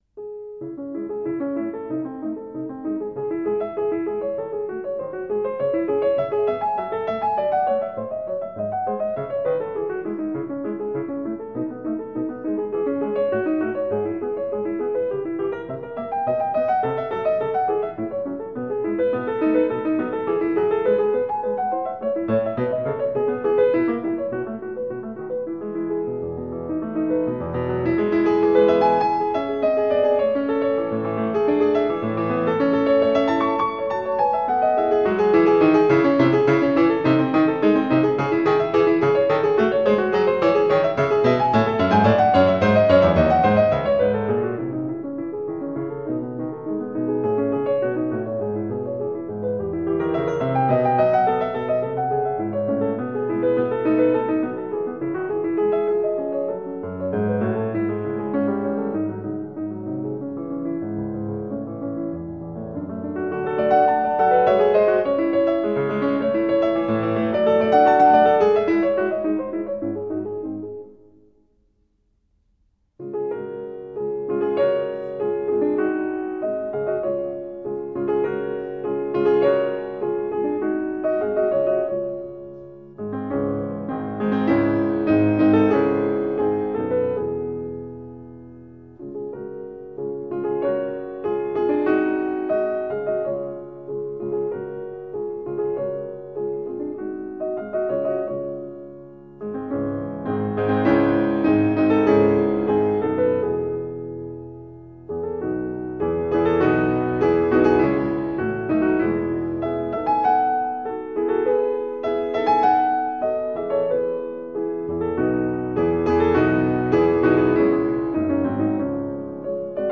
La pagina da cui partiamo, sempre di Schubert, è il quarto dei “Moments musicaux” con la pianista Maria João Pires.